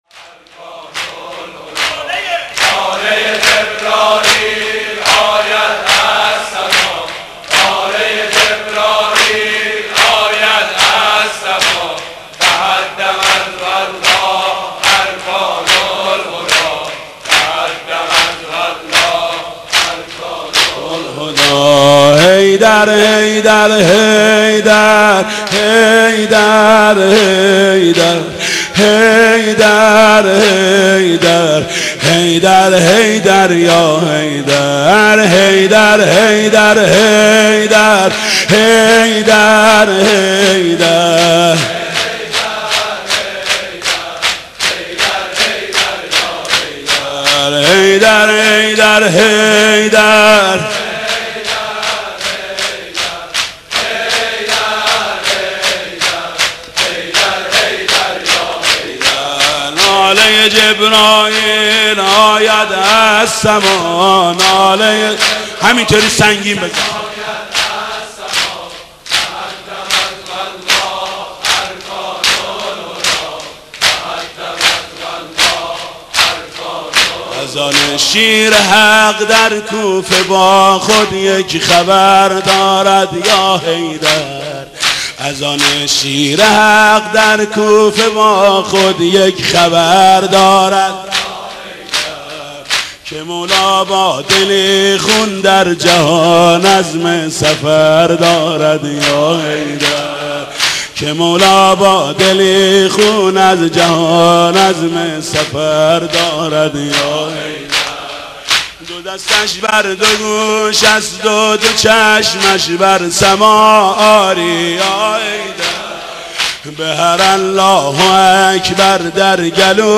زمینه: ناله ی جبرائیل آید از سرا